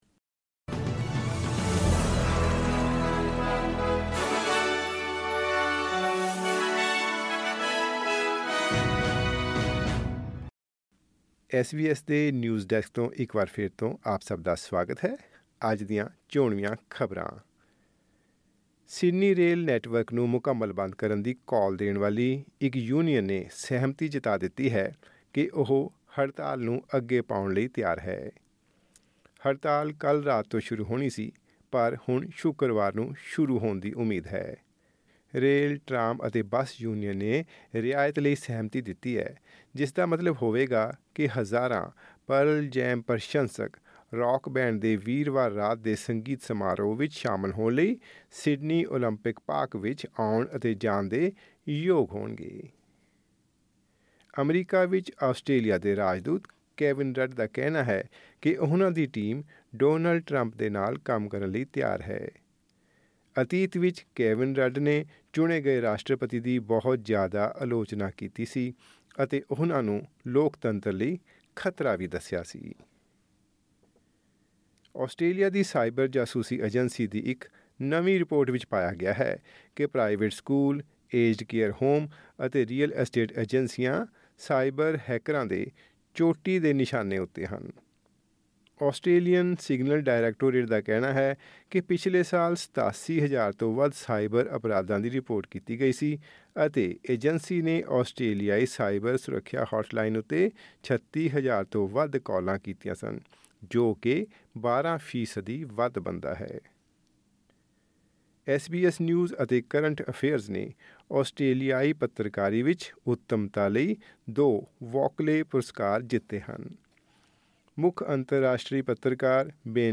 ਪੰਜਾਬੀ ਖ਼ਬਰਨਾਮਾ: ਸਿਡਨੀ ਰੇਲ ਨੈਟਵਰਕ ਨੂੰ ਜਾਮ ਕਰਨ ਵਾਲੀ ਹੜਤਾਲ ਇੱਕ ਦਿਨ ਲਈ ਮੁਲਤਵੀ